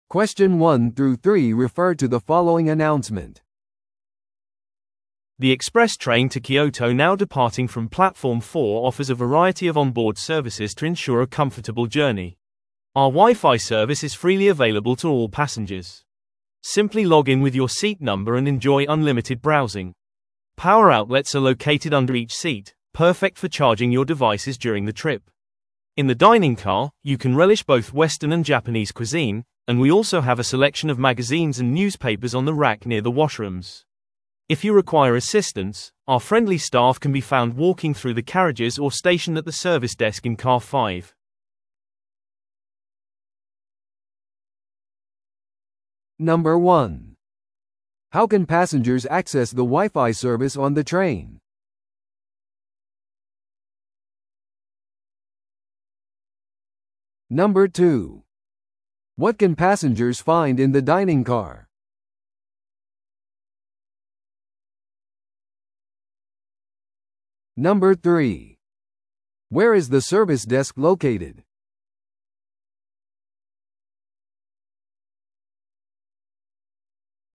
TOEICⓇ対策 Part 4｜特急列車のサービス案内 – 音声付き No.115